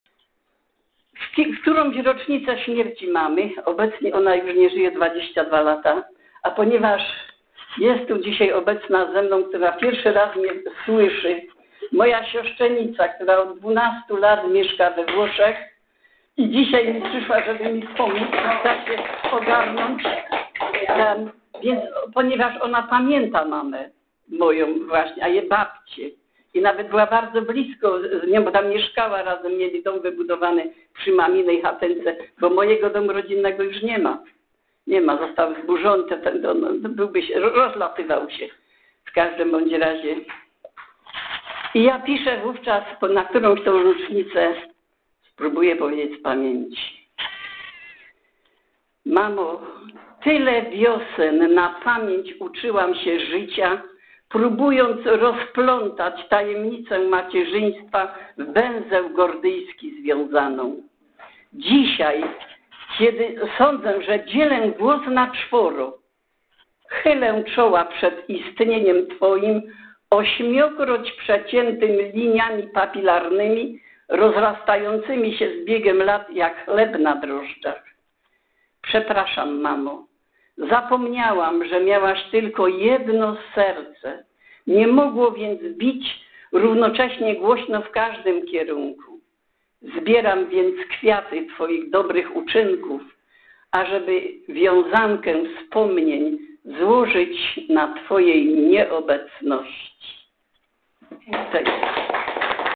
w siedzibie TMZŻ